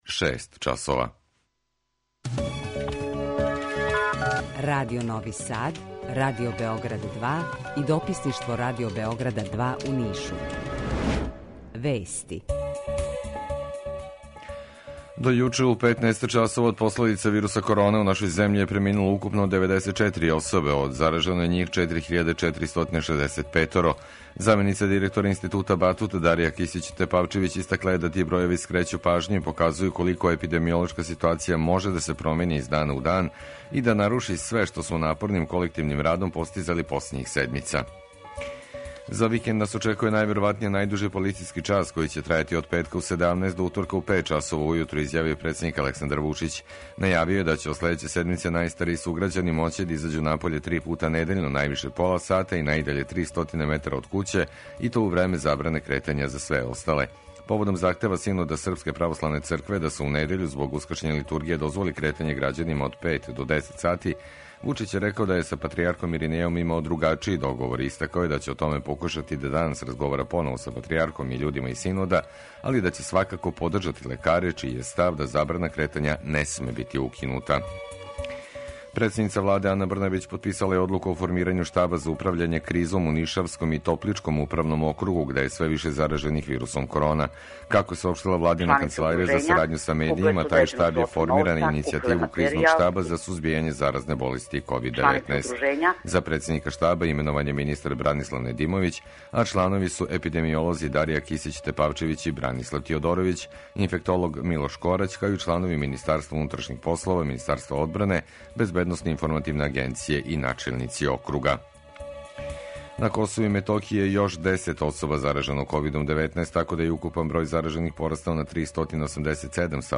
Укључење Радио Грачанице
Јутарњи програм из три студија
У два сата, ту је и добра музика, другачија у односу на остале радио-станице.